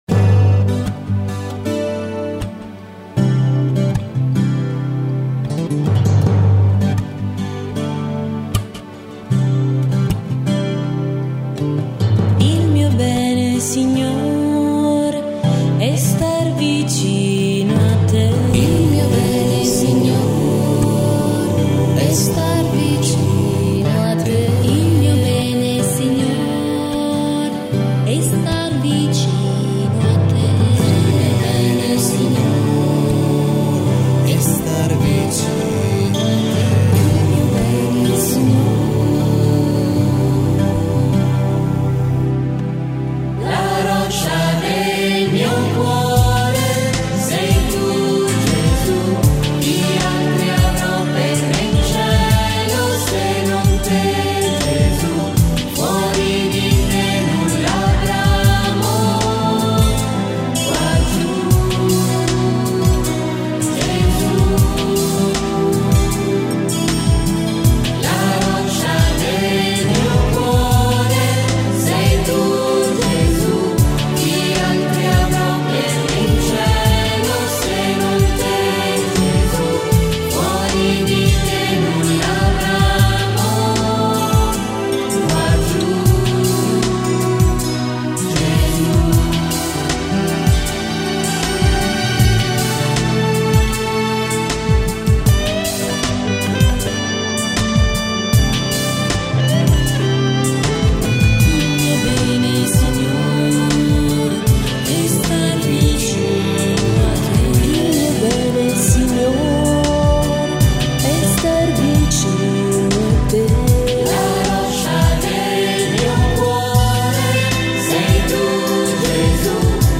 Canto per la Decina di Rosario e Parola di Dio: Il mio bene Signor